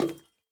Minecraft Version Minecraft Version 1.21.5 Latest Release | Latest Snapshot 1.21.5 / assets / minecraft / sounds / block / copper_bulb / step1.ogg Compare With Compare With Latest Release | Latest Snapshot
step1.ogg